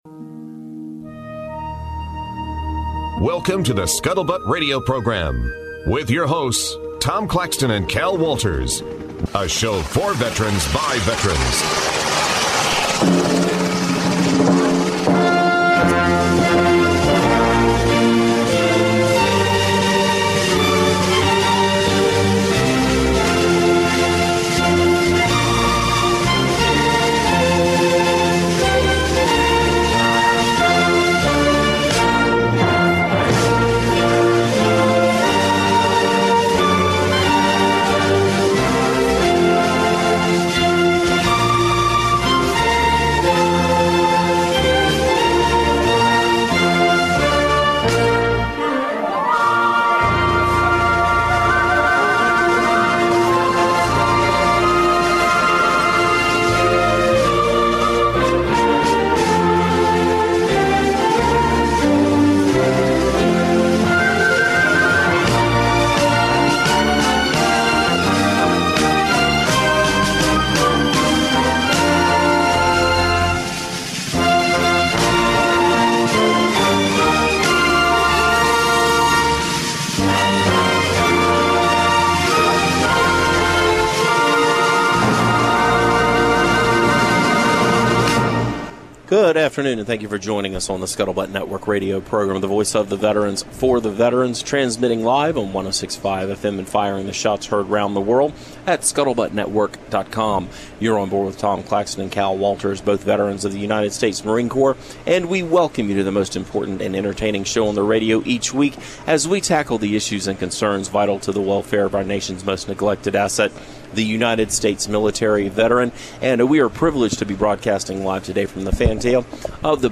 This show originates from onboard USS Alabama Battleship at Memorial Park in Mobile.